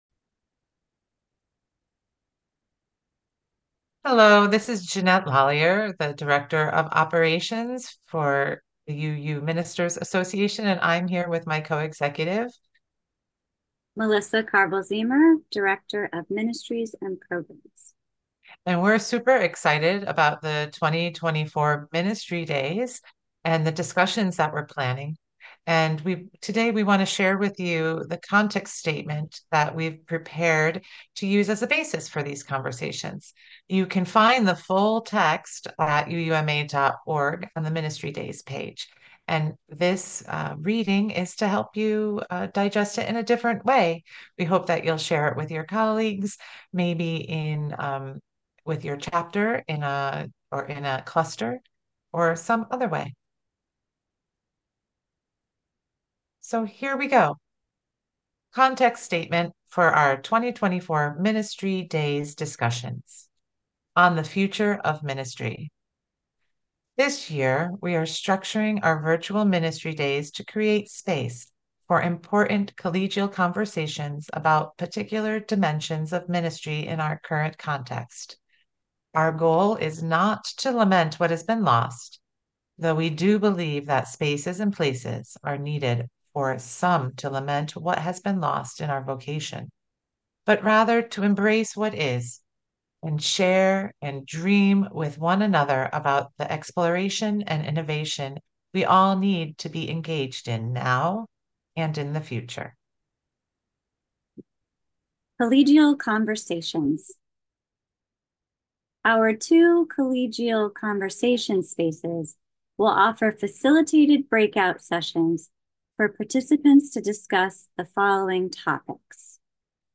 Here is a new slightly slower audio version .
We have created a recording of our new context statement.